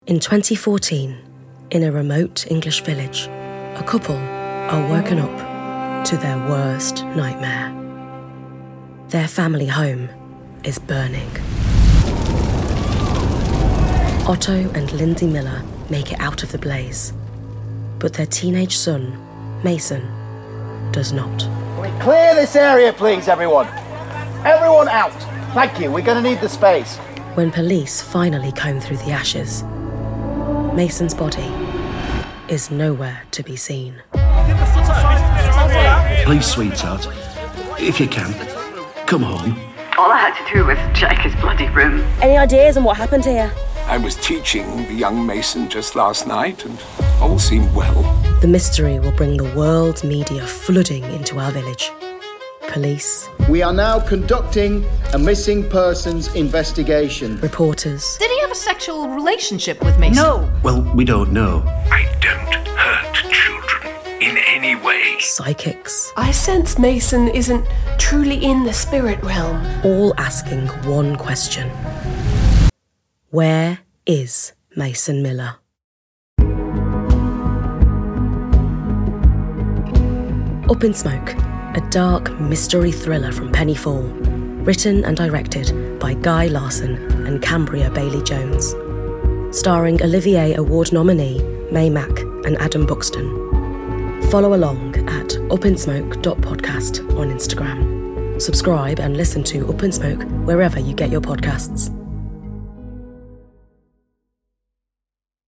Trailer
Adam Buxton and Mei Mac star in a true crime podcast about a fictional event.